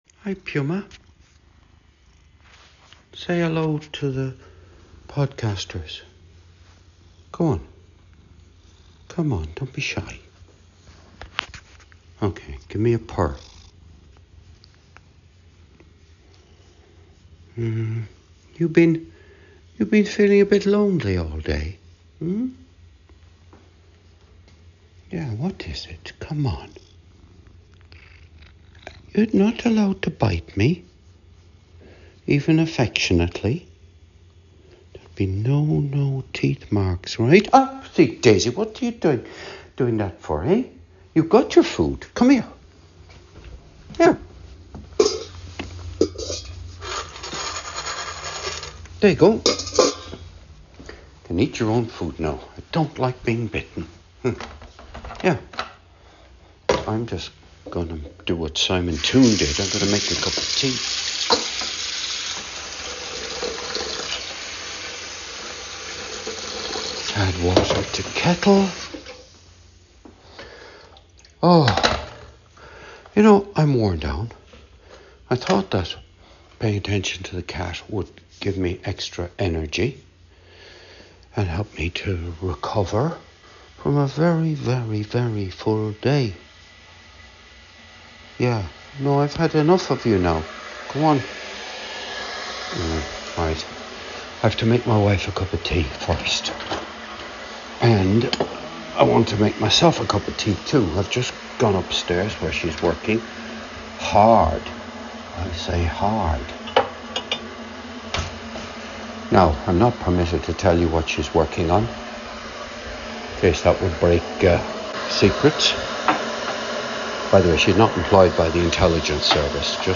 This was recorded on Wednesday 13th November in the kitchen.